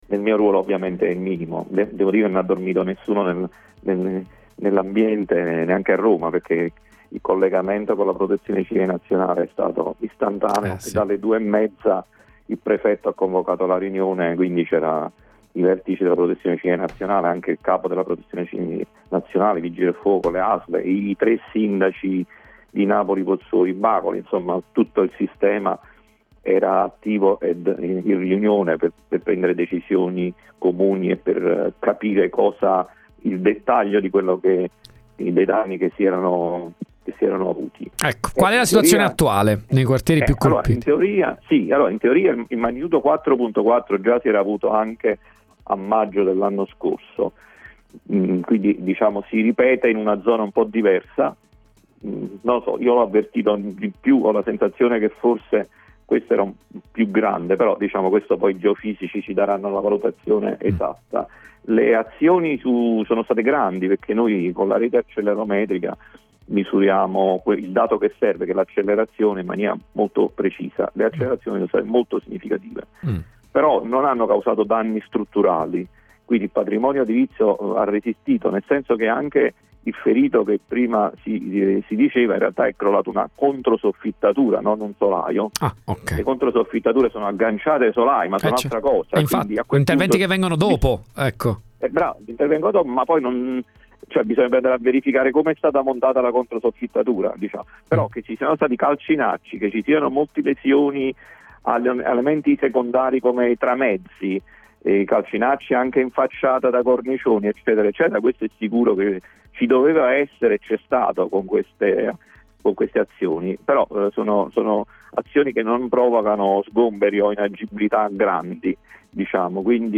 RIASCOLTA DA QUI L’INTERVISTA ALL’ASSESSORE DI NAPOLI ALLA PROTEZIONE CIVILE EDOARDO COSENZA